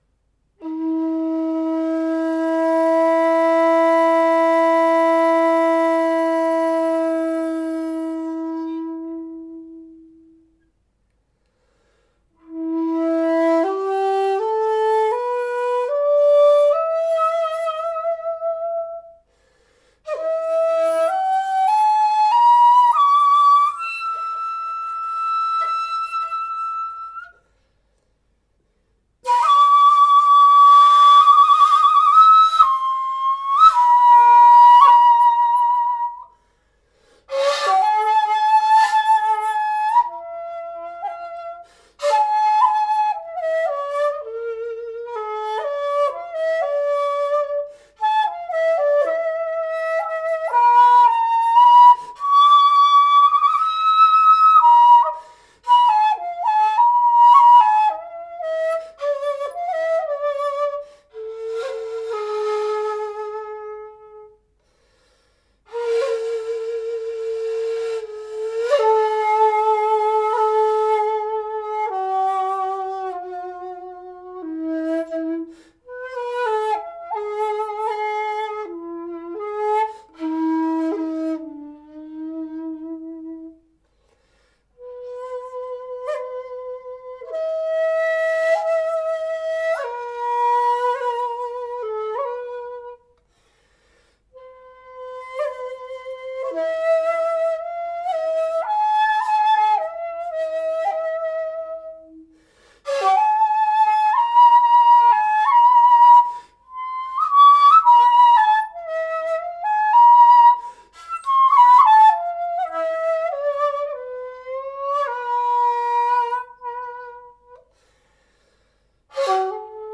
Restored 1.6 Ji-ari shakuhachi in E - Chikuyō | Atelier Chikudo